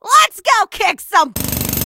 jackie_start_vo_01.ogg